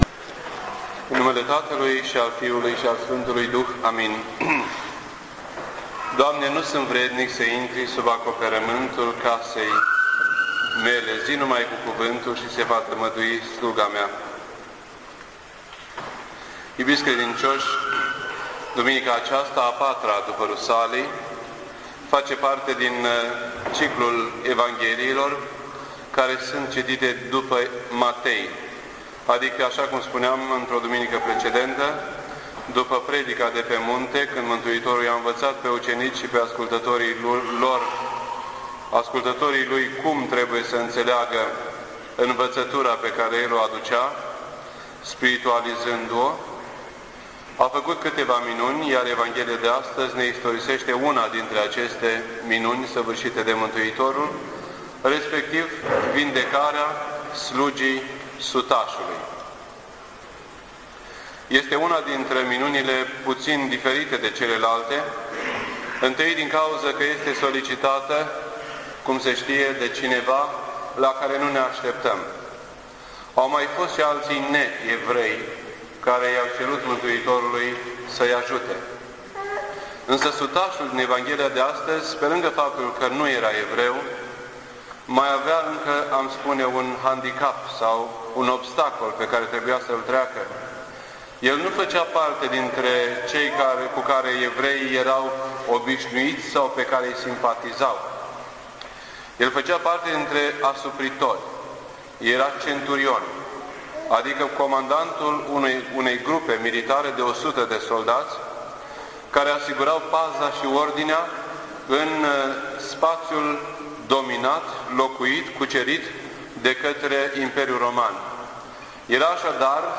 This entry was posted on Sunday, June 24th, 2007 at 10:15 AM and is filed under Predici ortodoxe in format audio.